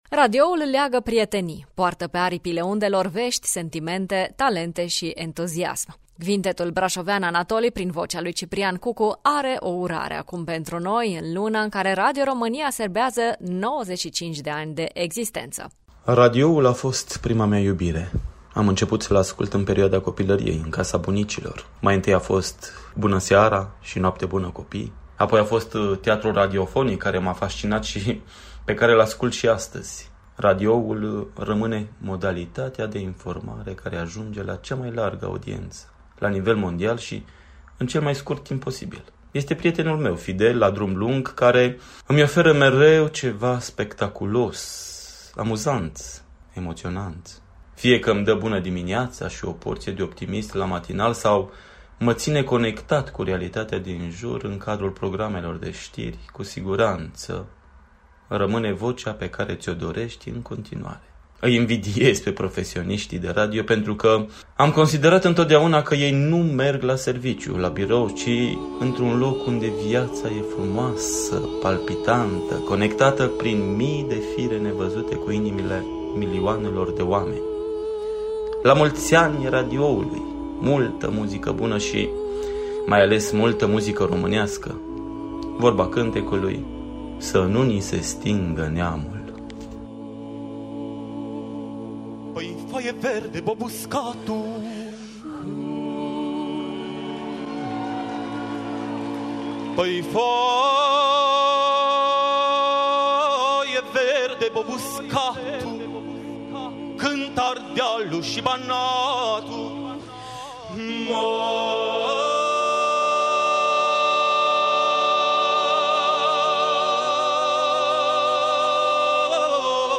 Bucuria de a fi | Urare de Ziua Radioului
tenor Cvintetul Anatoly